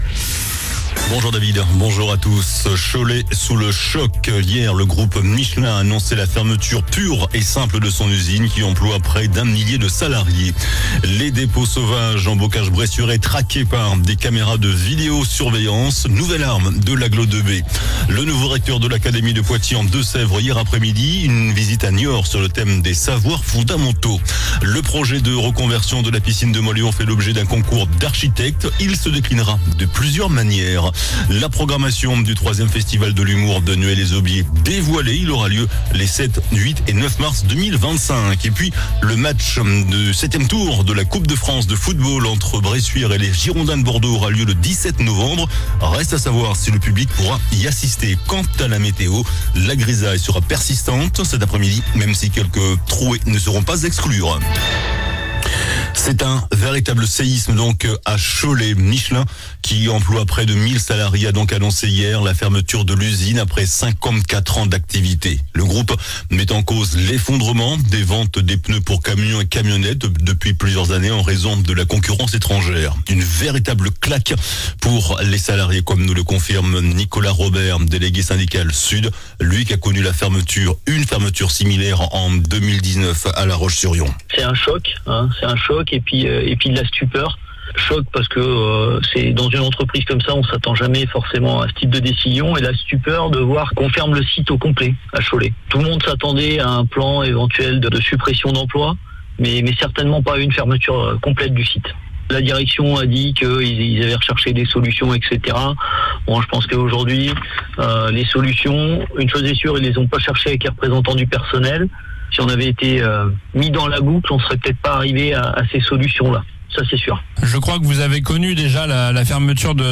JOURNAL DU MERCREDI 06 NOVEMBRE ( MIDI )